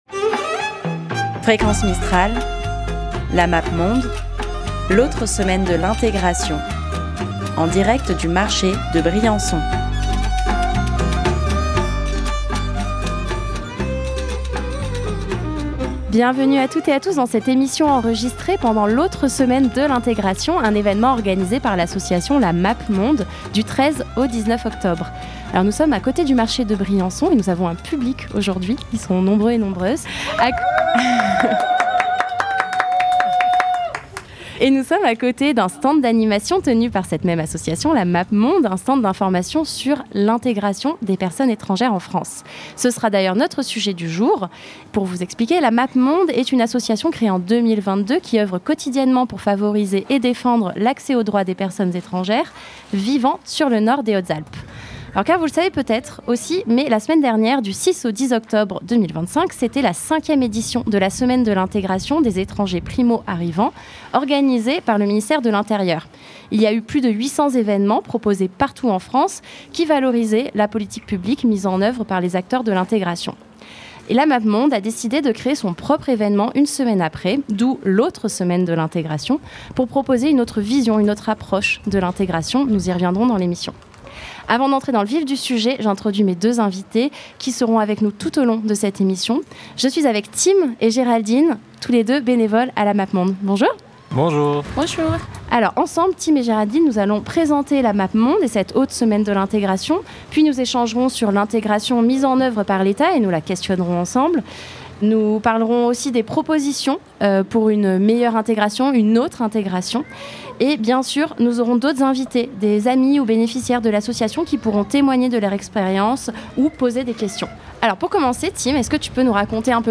Nous avons ainsi réalisé un plateau radio avec l'association à côté de leur stand d'information, près du marché de Briançon, mercredi 15 octobre.